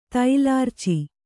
♪ tailārci